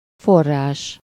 Ääntäminen
UK : IPA : /spɹɪŋ/